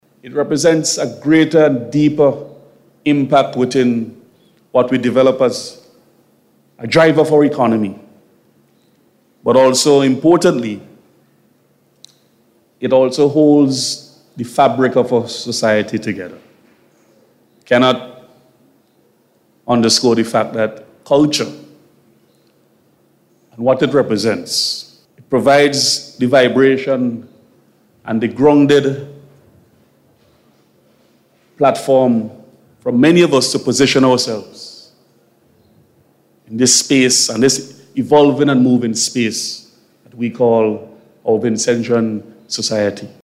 Speaking at the Media Launch of Vincy Mas 2025 Minister of Culture Carlos James said placing focus on combining design, media, the arts, technology and culture, will be a driving force for economic growth.